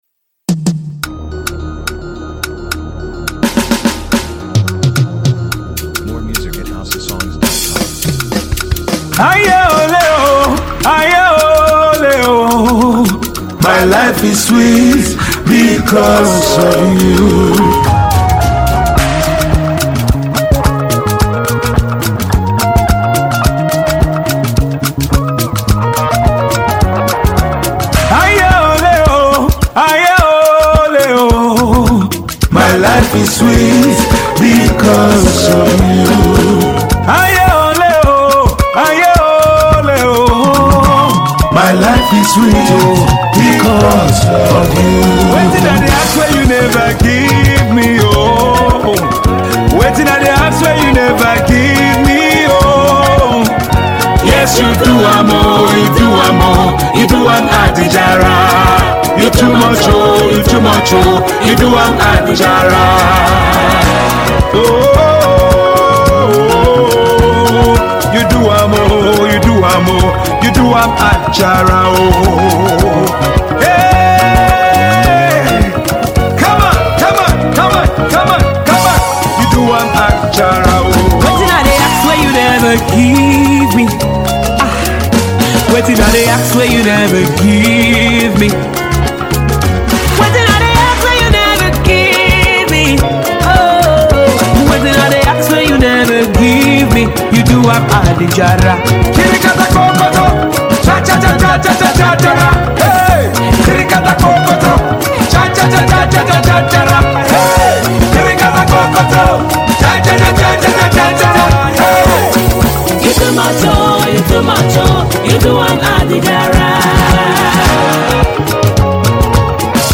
uplifts the spirit and soul
Tiv Song